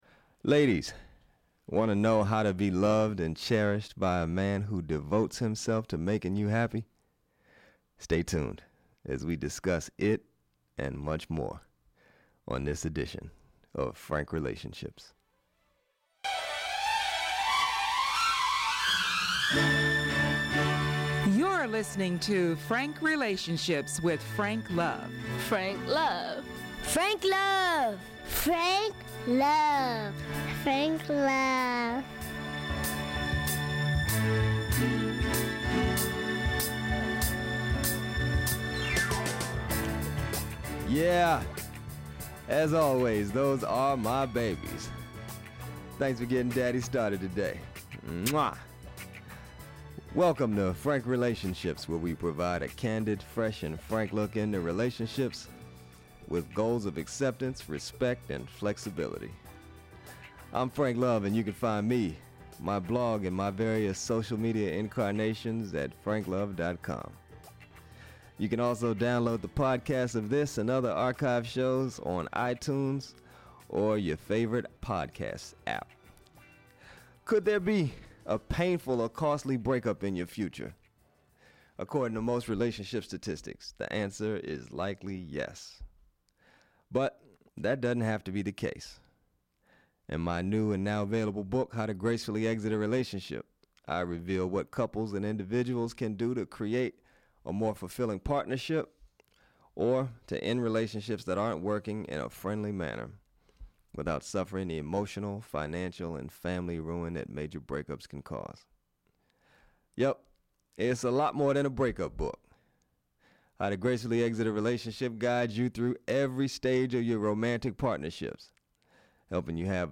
THE SURRENDERED WIFE Guests: Laura Doyle Date